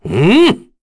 Kain-Vox_Happy4_kr.wav